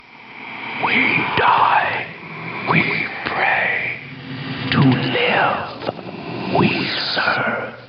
Voice line of Greeting from a Skeleton in Battlespire.